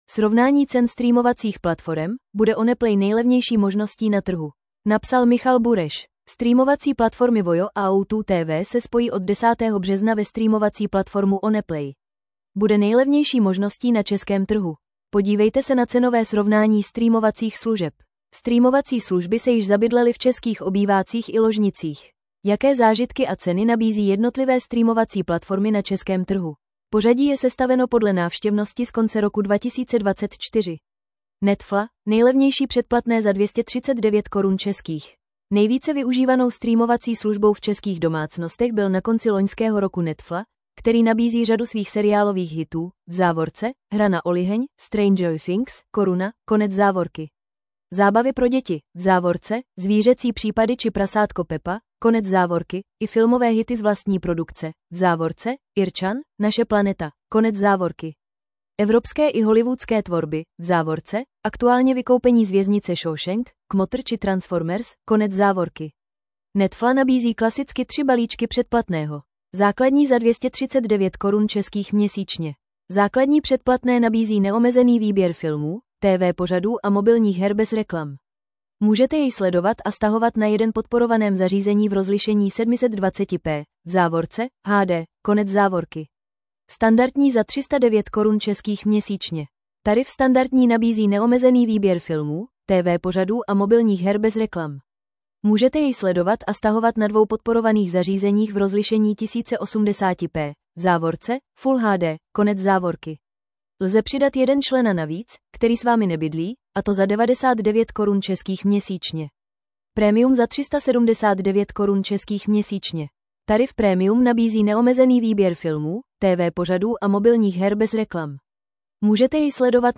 Rychlost přehrávání 0,5 0,75 normální 1,25 1,5 Poslechněte si článek v audio verzi 00:00 / 00:00 Tento článek pro vás načetl robotický hlas.